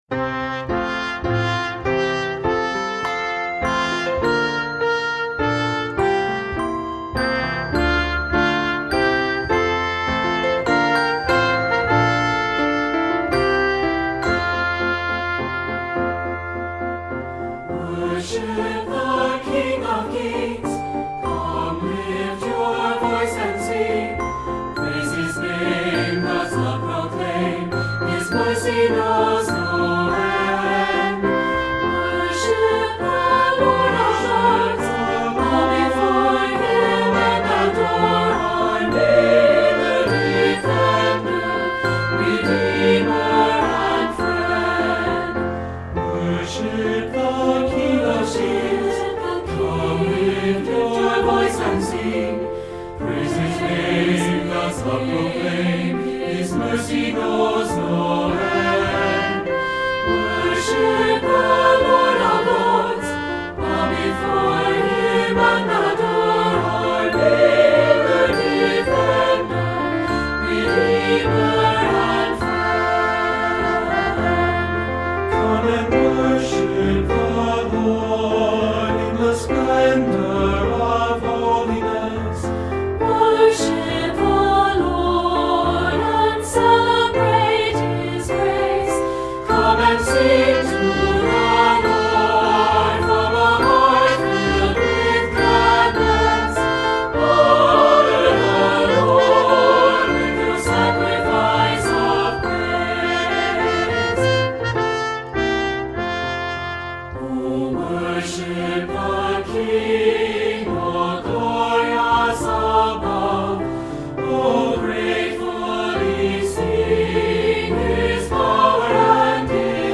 Voicing: 2-Part or SAB